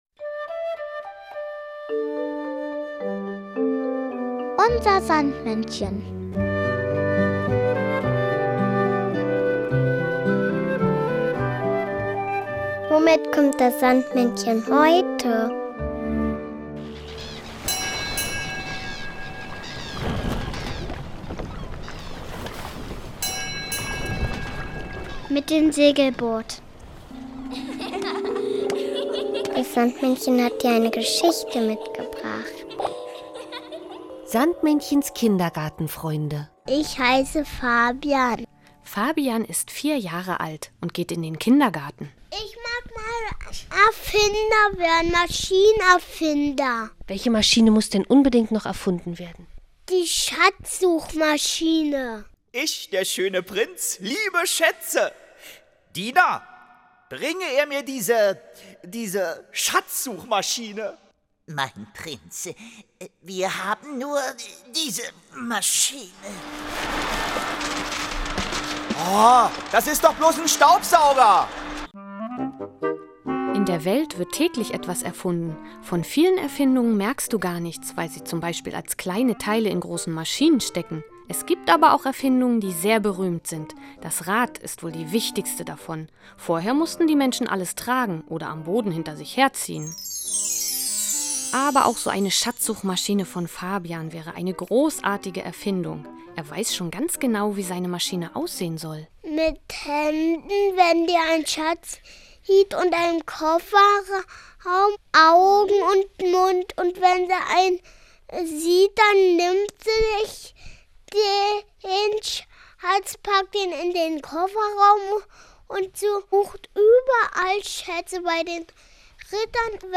Neben bekannten Figuren wie den Erdmännchen Jan und Henry, Kalli oder Pittiplatsch bietet der Podcast großartige Märchen und lustige Geschichten. Und das Beste: Man hört, mit welchem Fahrzeug das Sandmännchen heute vorbeikommt! UNSER SANDMÄNNCHEN hat aber nicht nur zauberhafte Hörspiele dabei, sondern auch noch ein passendes Lied und den berühmten Traumsand.